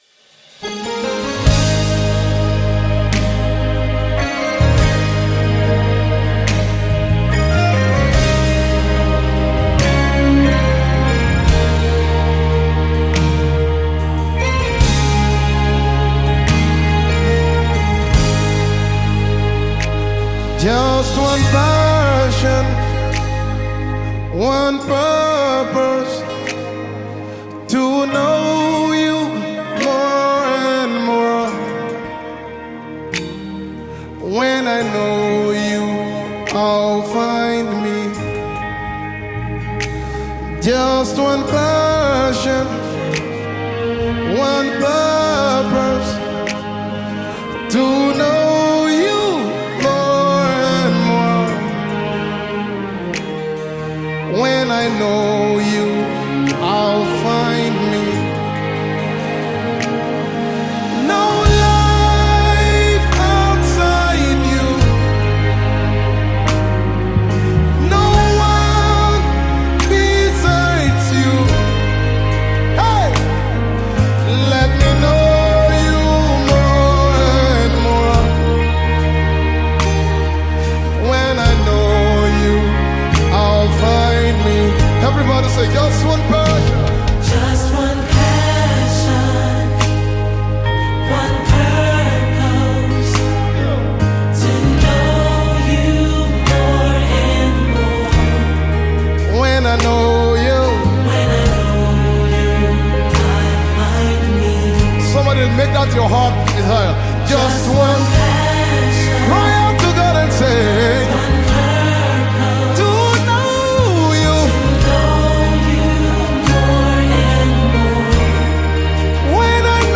worship songs